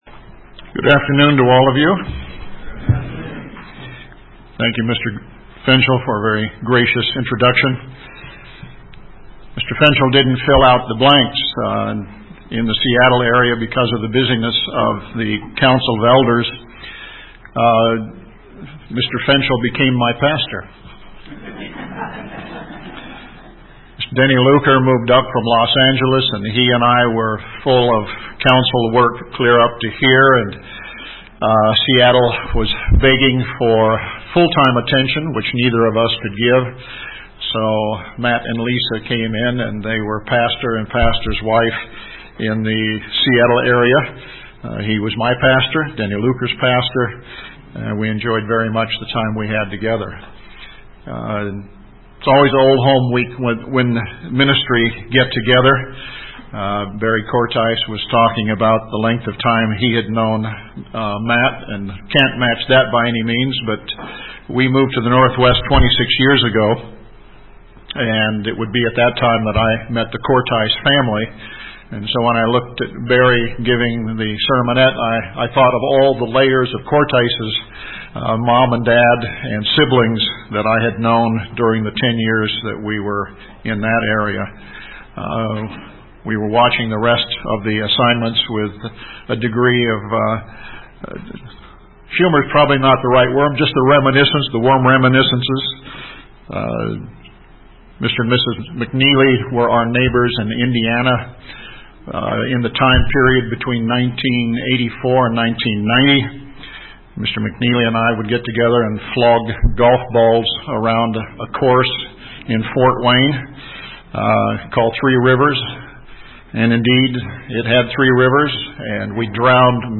This sermon was given at the Canmore, Alberta 2015 Feast site.